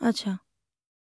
TALK 4.wav